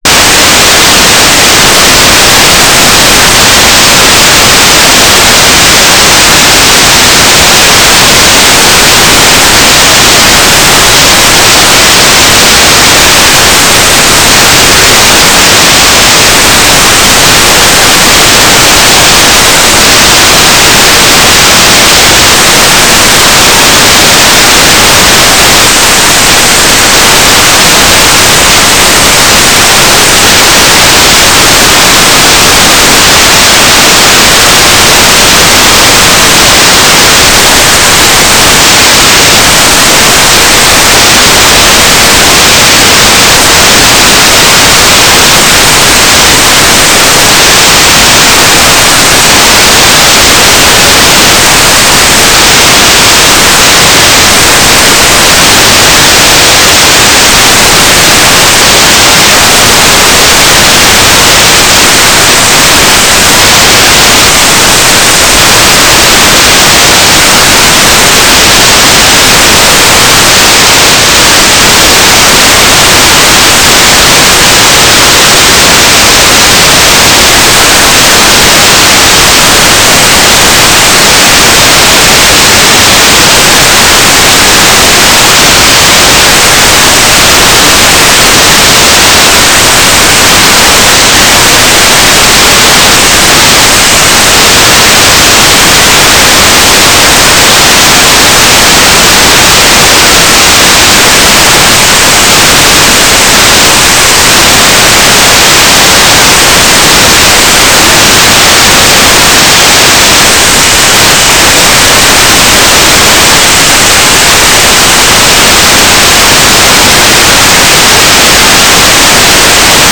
"transmitter_mode": "FSK",
"transmitter_baud": 9600.0,